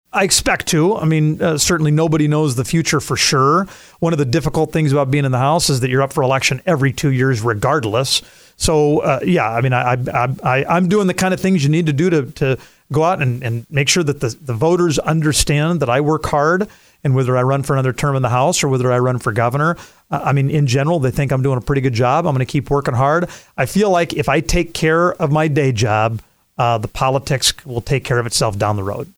During a stop at the DRG Media Group radio stations Wednesday afternoon, Dusty Johnson said he might for governor.